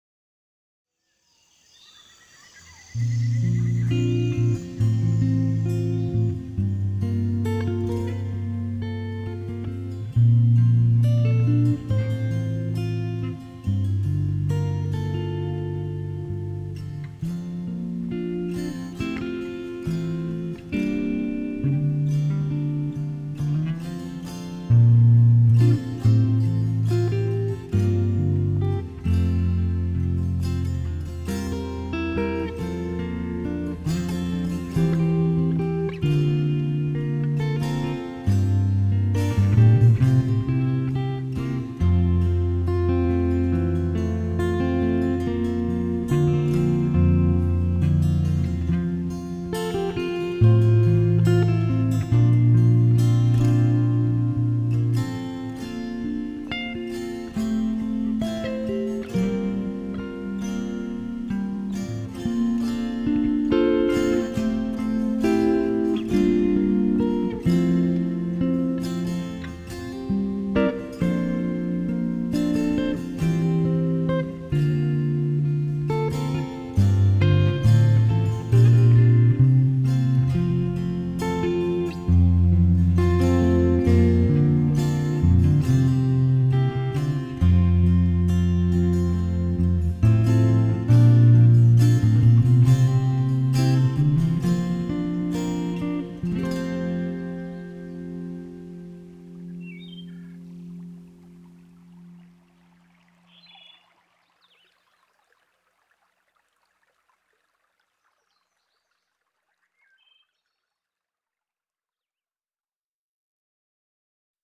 gentle, harmonically deep